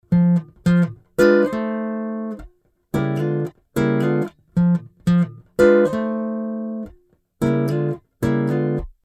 Кусок на три квадрата со всего двумя аккордами, а не могу снять второй... Первый E, а потом?